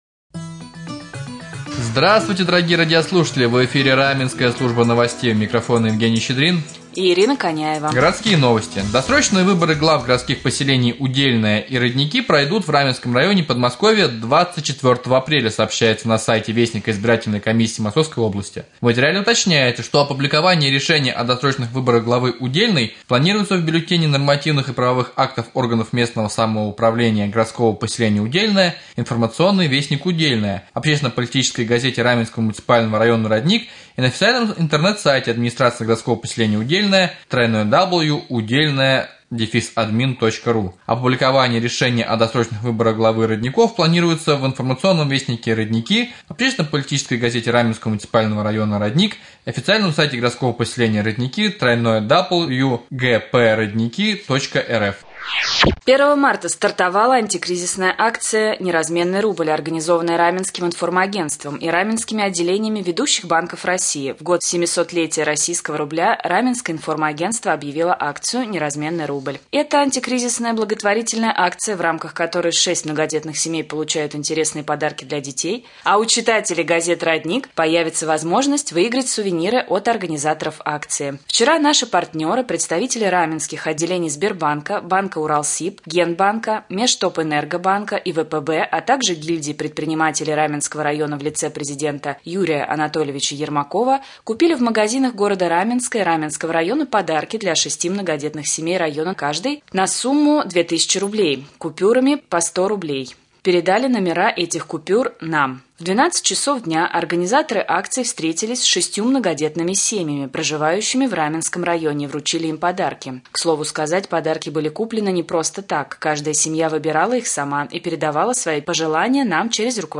1. В прямом эфире глава района Владимир Демин 2. Новости